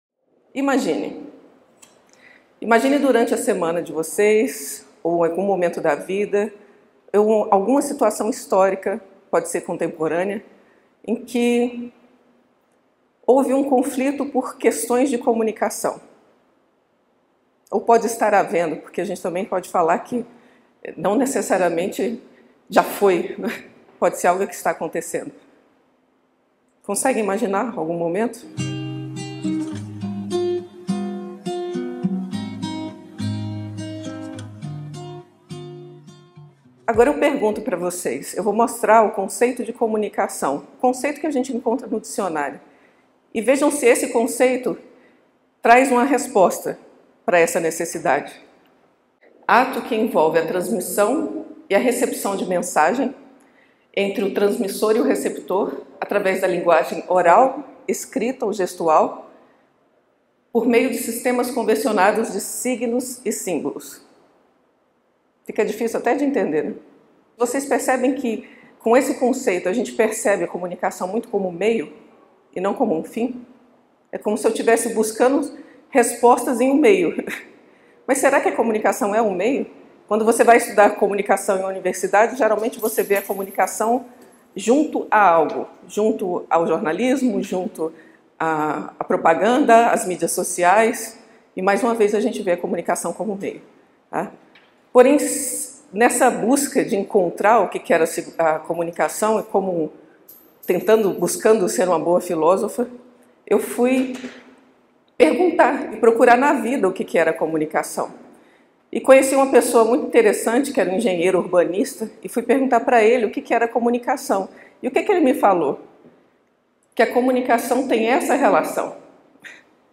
Palestras Filosóficas